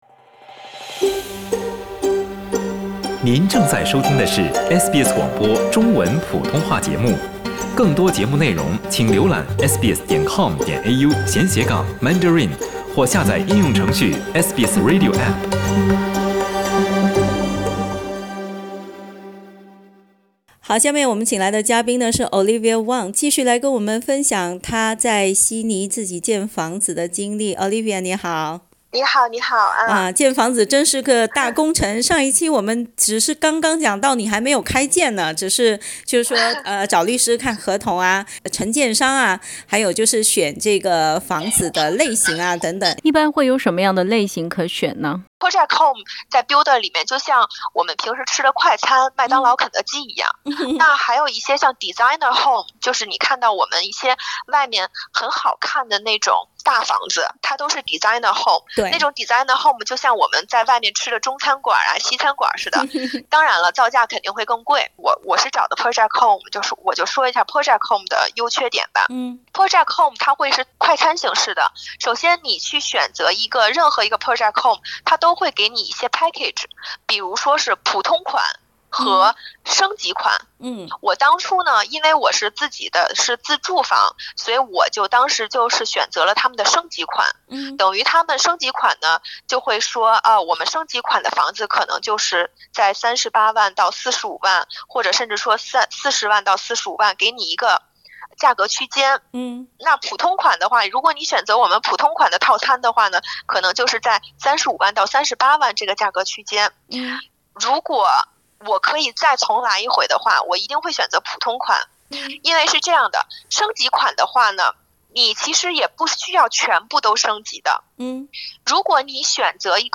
（请听采访，本节目为嘉宾个人体验，仅供参考） 澳大利亚人必须与他人保持至少1.5米的社交距离，请查看您所在州或领地的最新社交限制措施。